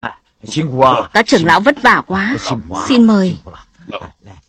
Thể loại: Câu nói Viral Việt Nam
Description: Các trưởng lão vất vả quá, xin mời... là một sound effect meme hài hước được sử dụng rộng rãi trong các video edit trên mạng xã hội. Âm thanh này thường xuất hiện khi nhân vật hoặc tình huống đạt đến cao trào gây cười, mang lại cảm giác châm biếm, tấu hài.